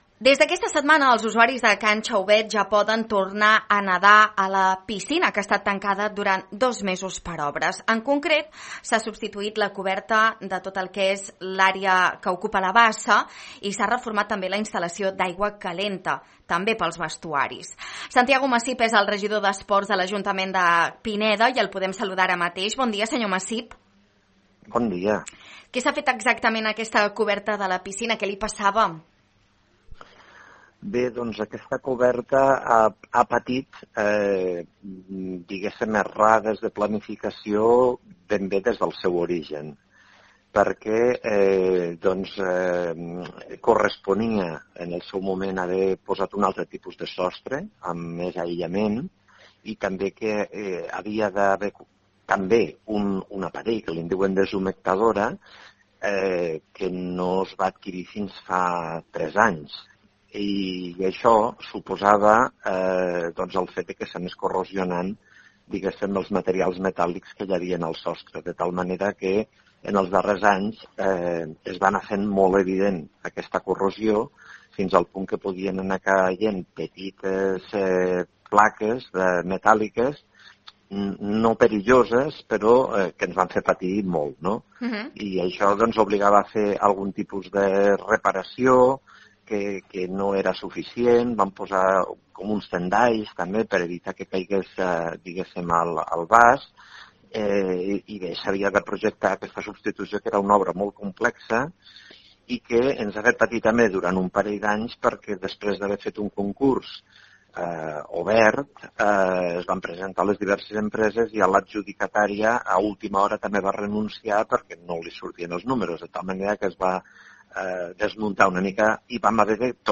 3509-ENTREVISTA-MILLORA-CAN-XAUBET-–-SANTIAGO-MACIP.mp3